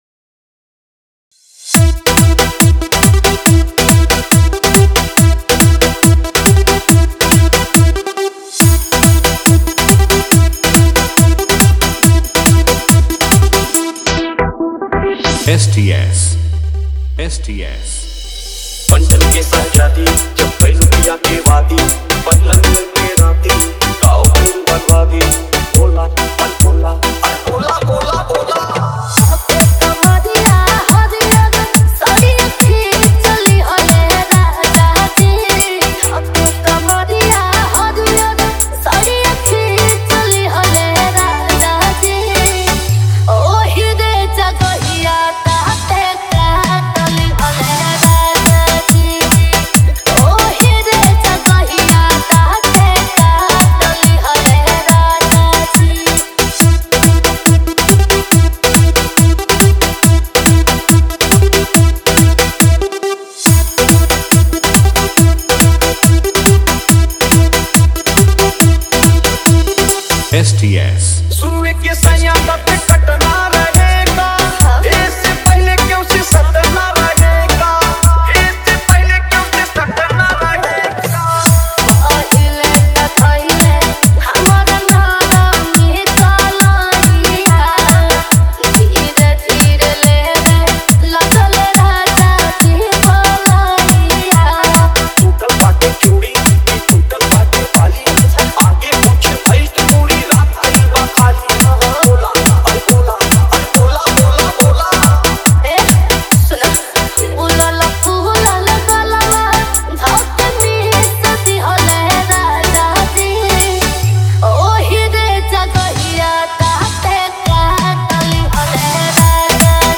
Bhojpuri DJ remix mp3 download
Bhojpuri dance DJ song